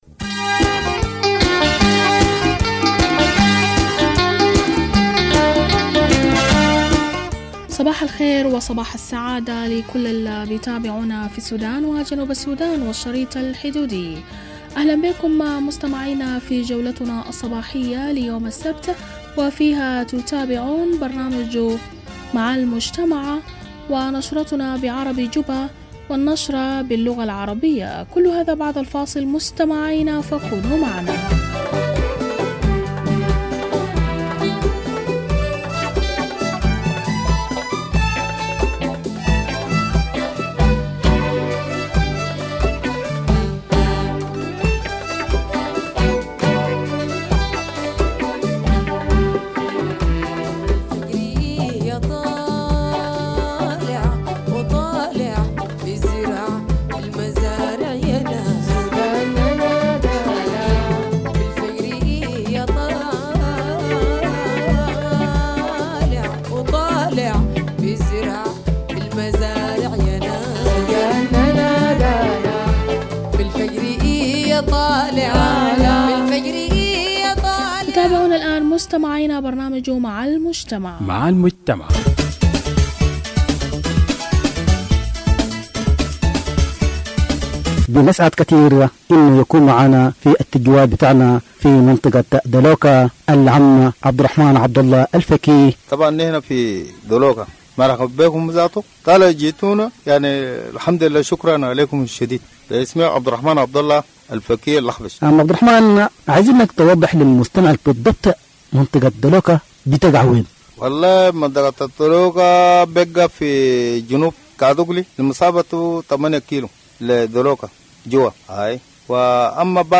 Morning Broadcast 02 August - Radio Tamazuj